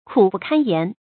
苦不堪言 kǔ bù kān yán 成语解释 堪：能。苦痛至极；不能用言语来形容、表述。
成语简拼 kbky 常用程度 常用成语 感情色彩 中性成语 成语用法 补充式；作谓语、宾语；形容十分痛哭 成语结构 主谓式成语 产生年代 古代成语 成语正音 堪，不能读作“shènɡ”。